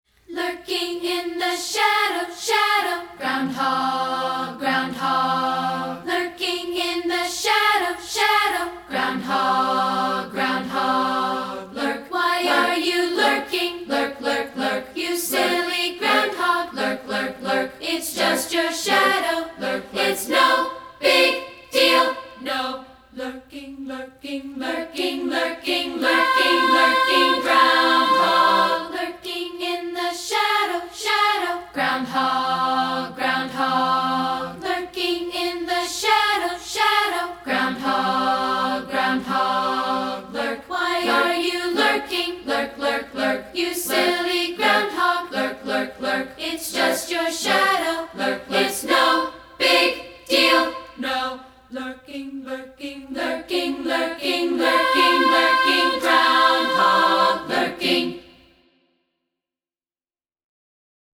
A Cappella
we've created an a cappella version in MP3 format.